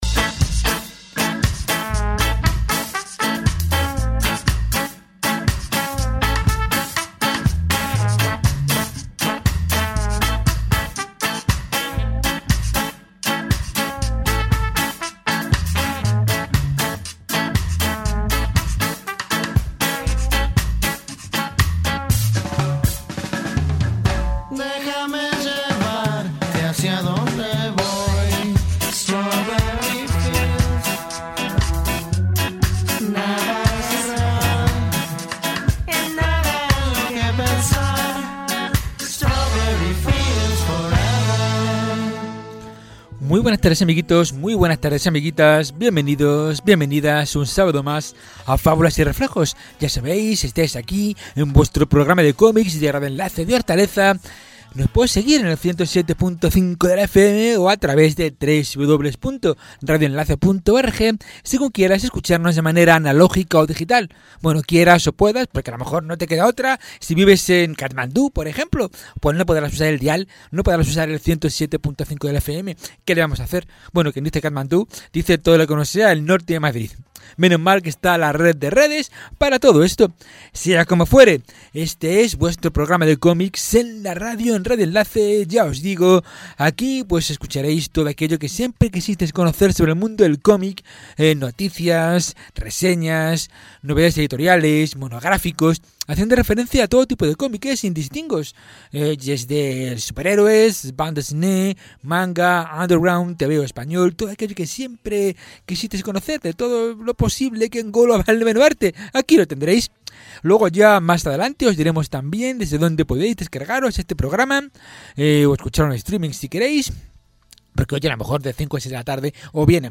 Por eso es que os traemos los programas del mes de Junio con cantidad de títulos, humor, además de cine, editoriales y gran cantidad de autores, todo ello siempre acompañado de noticias y la mejor música posible.